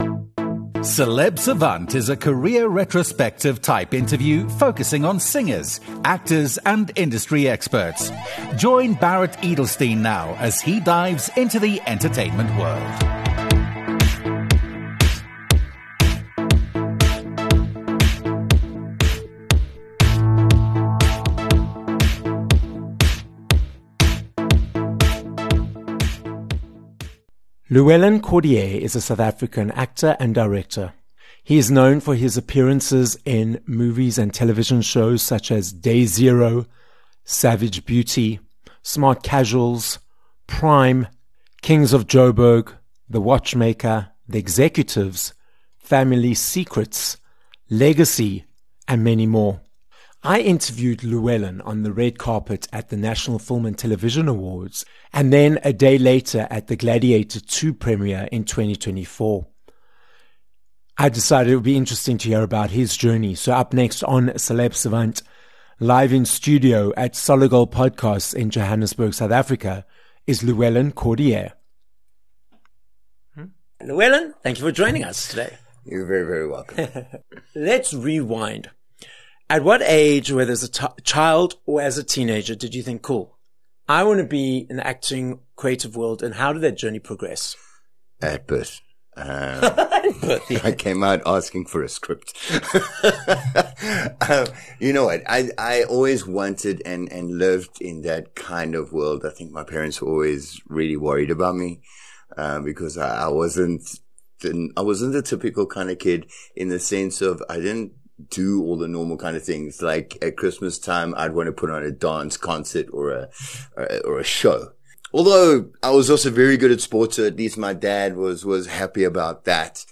We also hear why he loves acting and about his latest project, Kings of Joburg season 3. This episode of Celeb Savant was recorded live in studio at Solid Gold Podcasts, Johannesburg, South Africa.